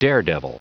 Prononciation du mot : daredevil